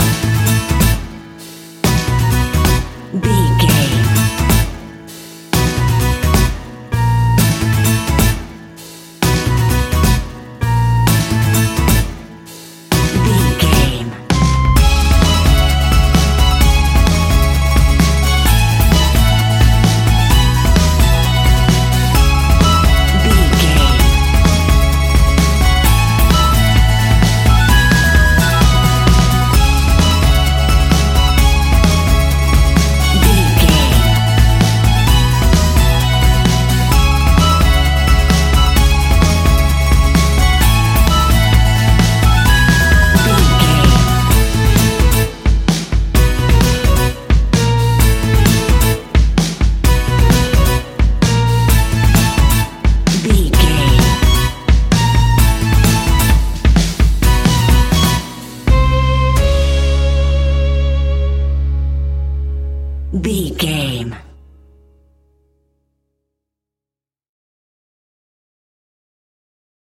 Aeolian/Minor
C#
acoustic guitar
mandolin
ukulele
double bass
accordion